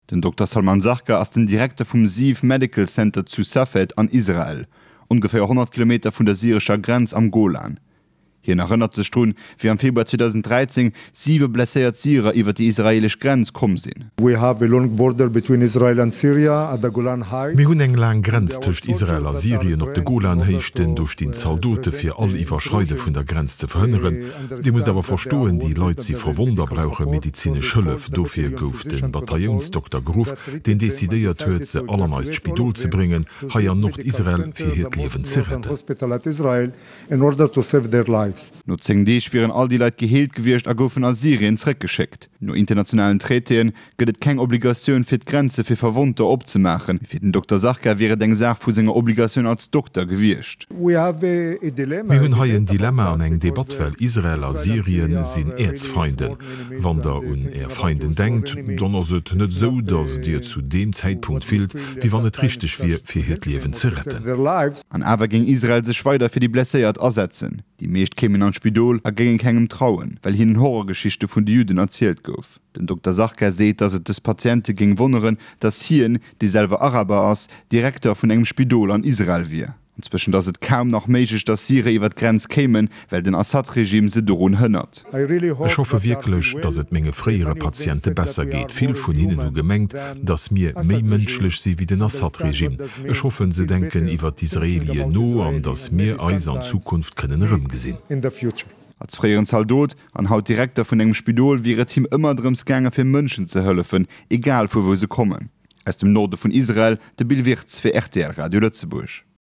[Reportage] Israelesch medizinesch Hëllefsaktioun un der syrescher Grenz
Dëse Reportage gouf fir RTL Radio Lëtzebuerg produzéiert ginn, an ass den 1. November iwwerdroe ginn.